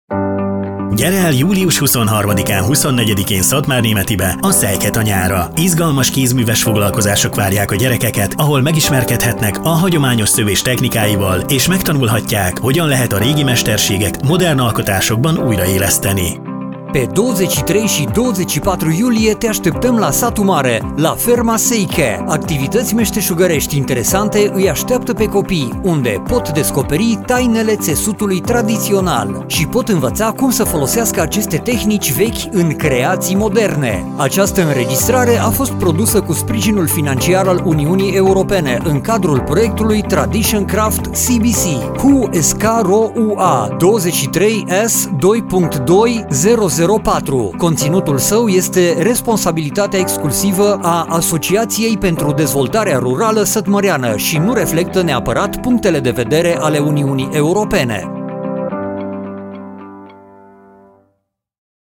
Radio spot - Atelier pentru copii - Kézműves műhely gyerekeknek